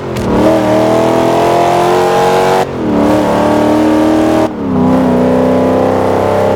fourth_cruise.wav